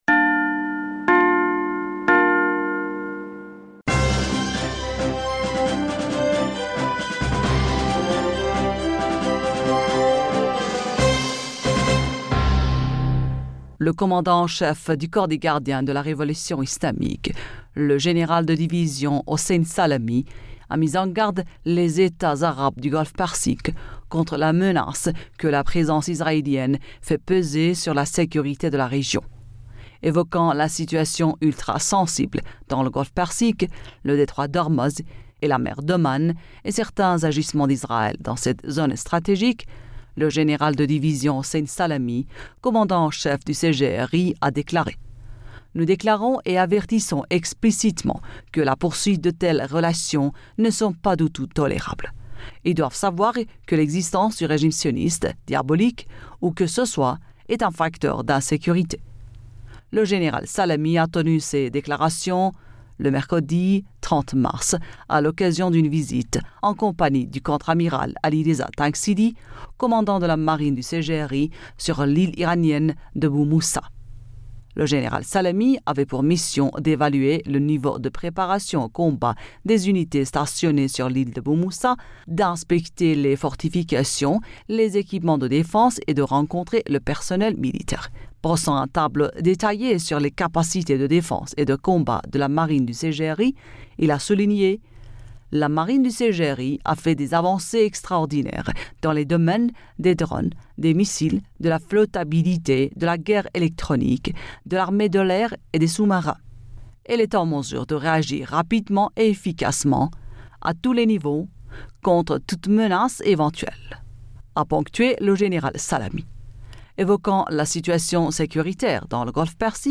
Bulletin d'information Du 31 Mars 2022